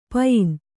♪ payin